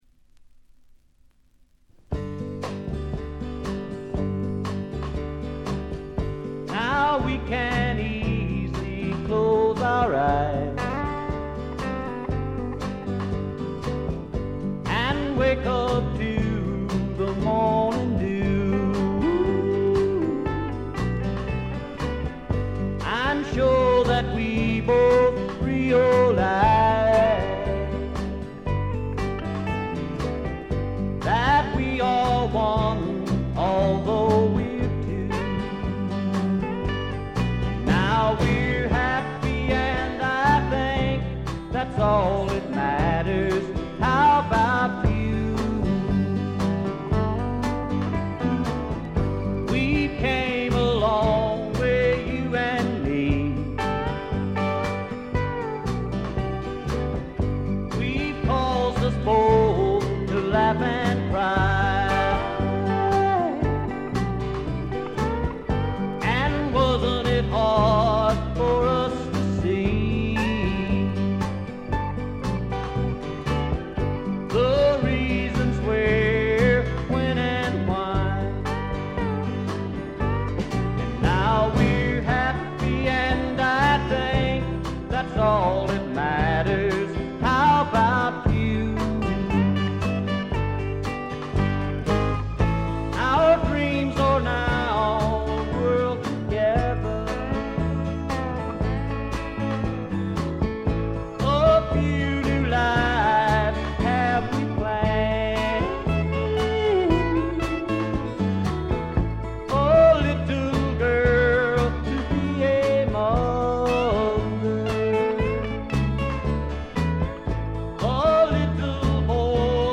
ごくわずかなノイズ感のみ。
謎のシンガー・ソングライター好盤です。
時代を反映してか、ほのかに香る土の匂いがとてもいい感じですね。
試聴曲は現品からの取り込み音源です。
Engineered At - Gold Star Studios